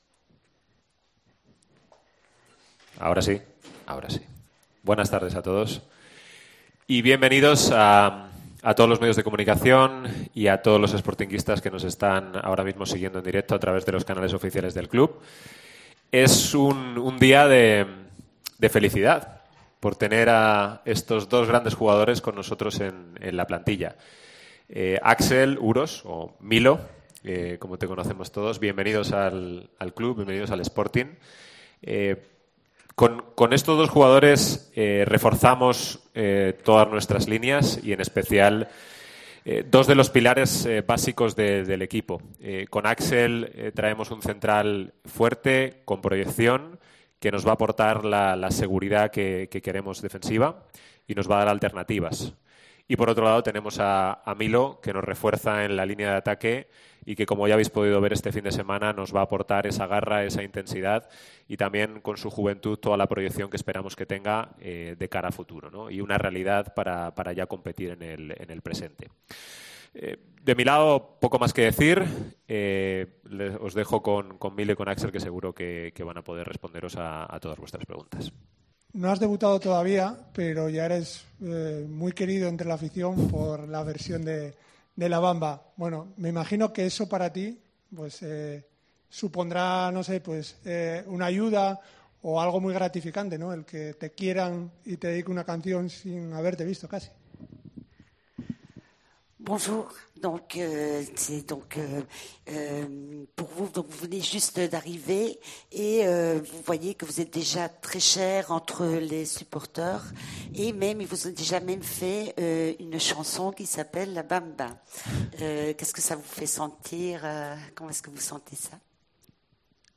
El central francés fue asistido por una traductora para poder entender y responder a las preguntas de los periodistas.